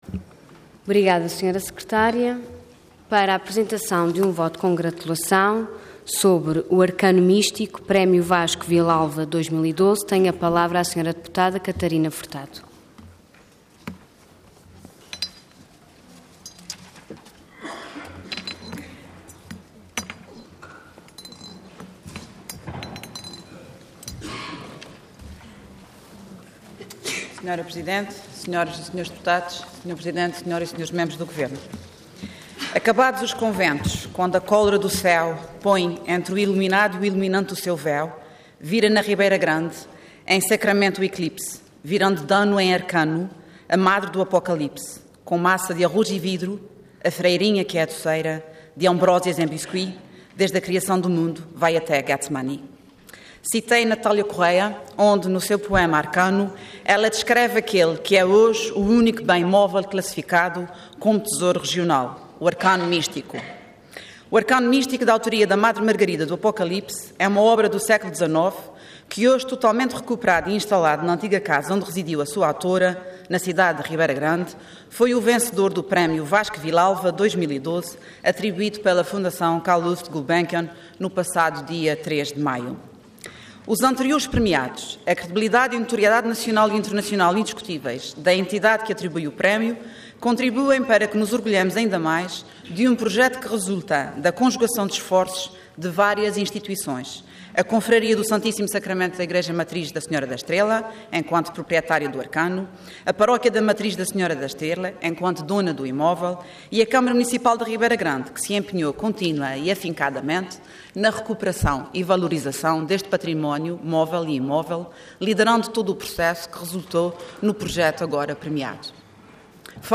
Intervenção Voto de Congratulação Orador Catarina Moniz Furtado Cargo Deputada Entidade PS